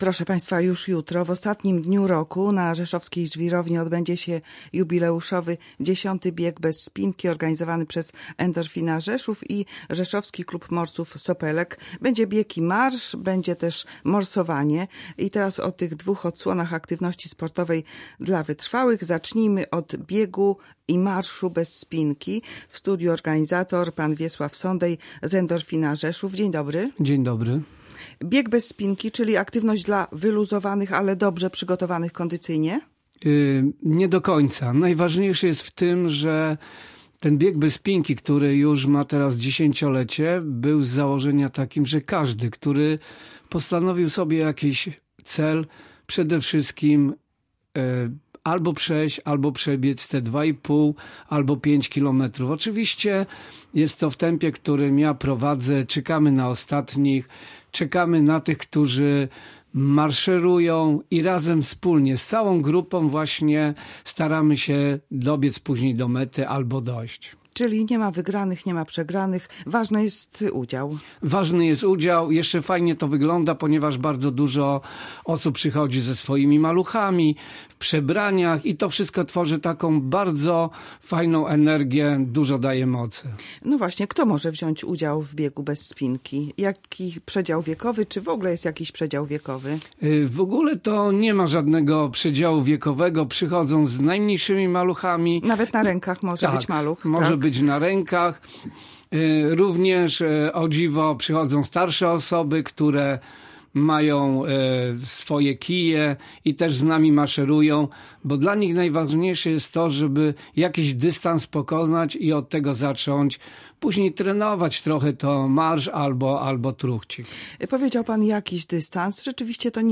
Rozmowę